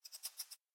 sounds / mob / rabbit / idle4.ogg